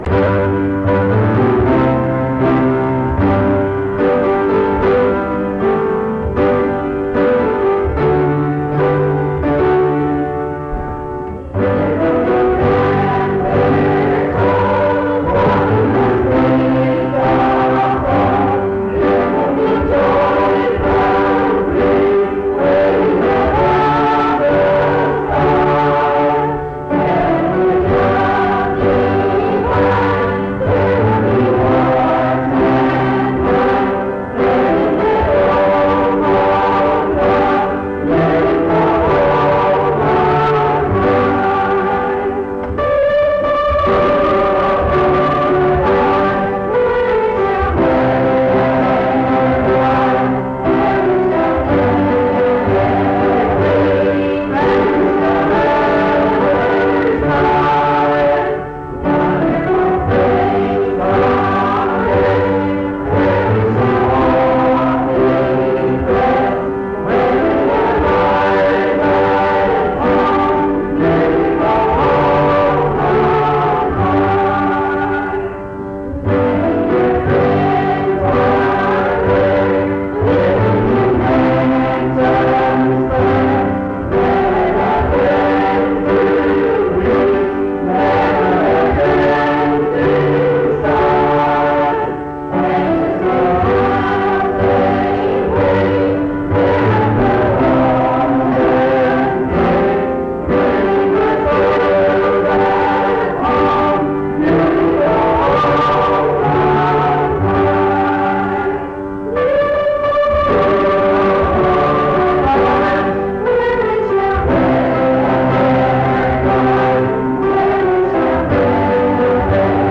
Beautiful Home on High Item eb7f75cd5afdd51388e543872bf052fceb973315.mp3 Title Beautiful Home on High Creator Highland Park Methodist Church Choir Description This recording is from the Monongalia Tri-District Sing. Mount Union Methodist Church II, rural, Monongalia County, WV, track 145I.